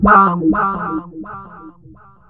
VOX FX 6  -L.wav